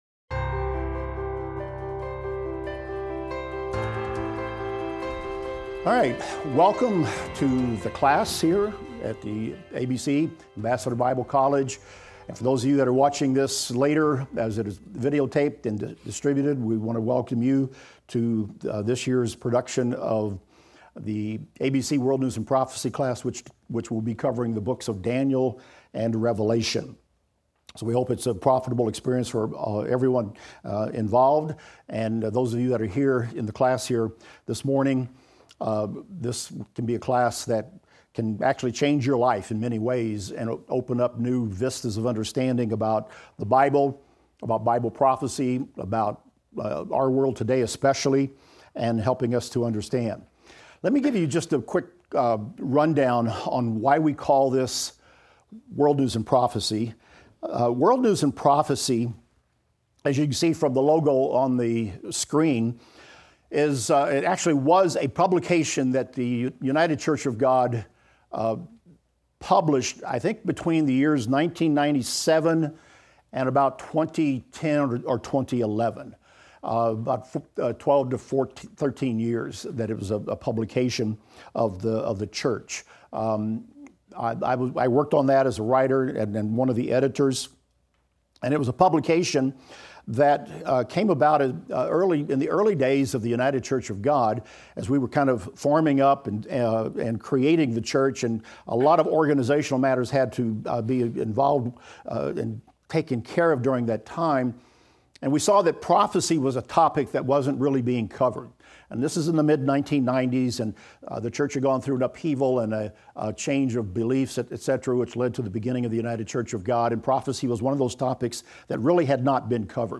Daniel - Lecture 1 - audio.mp3